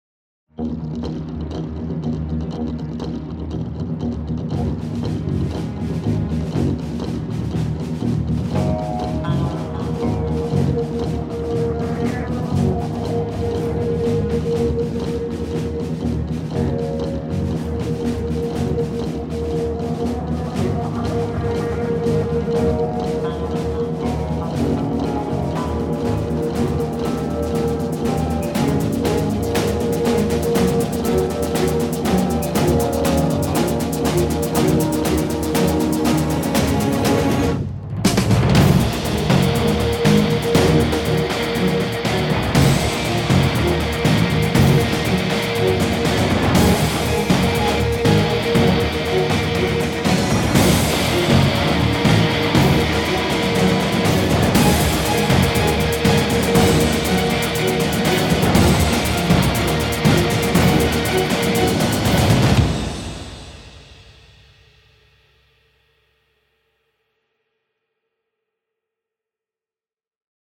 Epic track for trailers and action.
Rhythmic epic track for trailers and action.